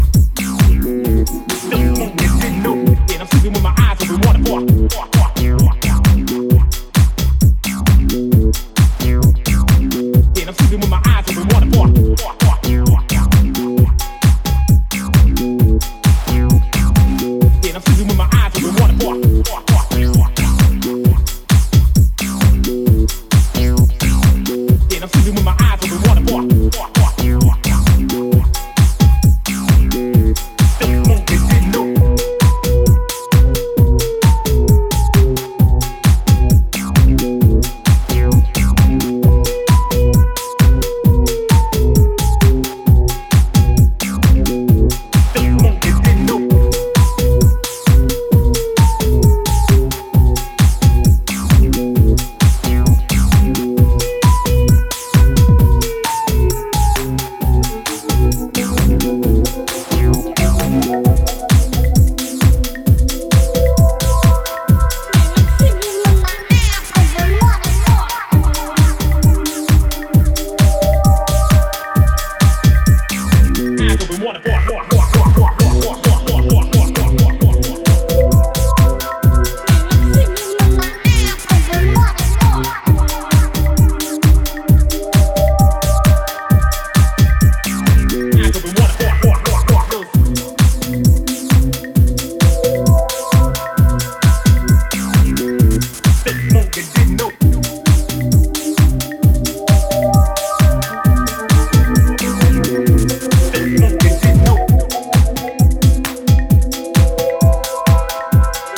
subtle and elegant